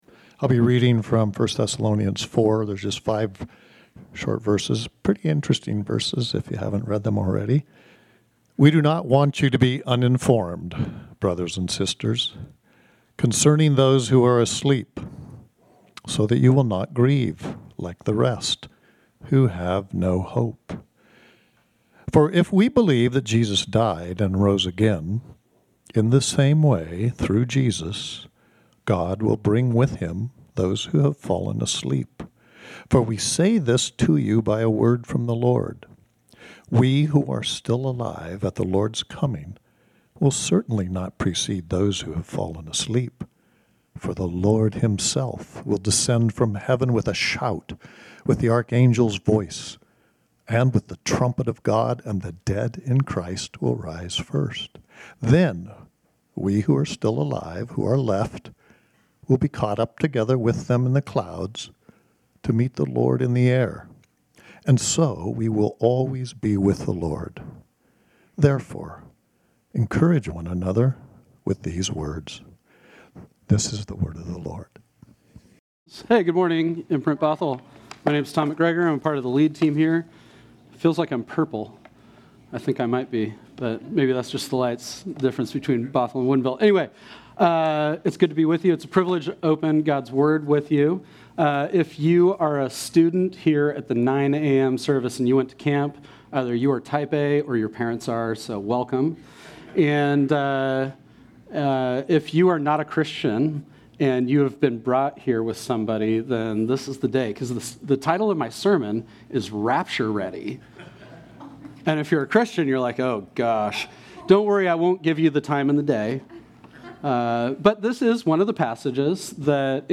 This sermon was originally preached on Sunday, August 3, 2025.